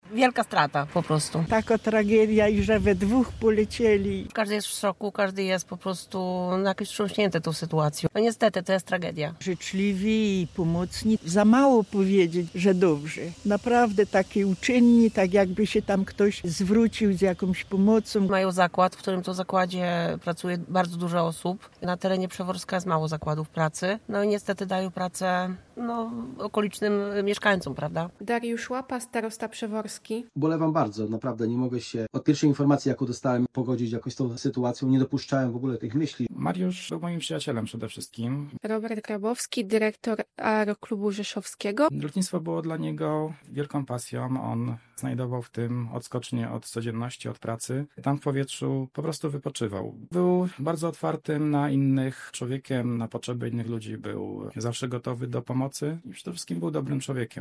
Znajomi i mieszkańcy rodzinnej miejscowości mówią zgodnie, że to ogromna tragedia: